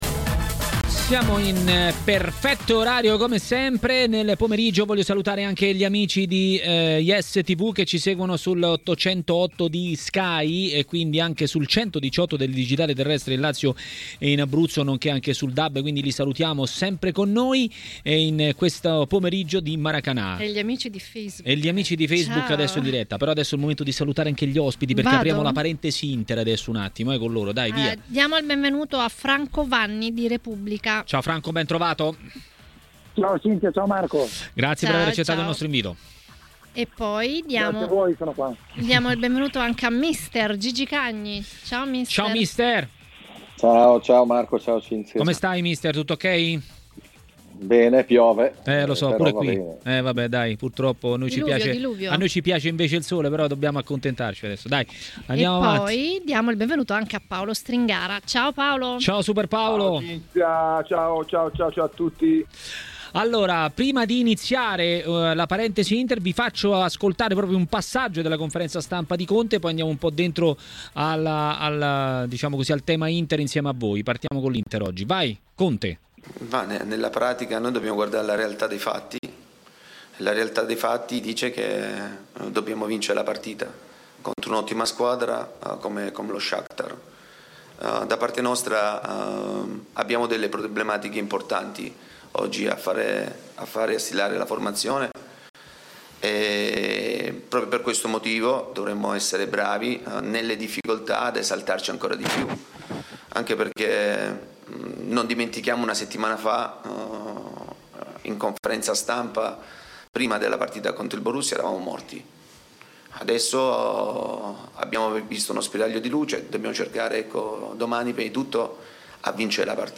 Per commentare la giornata di Champions League a TMW Radio, durante Maracanà, è intervenuto Gigi Cagni.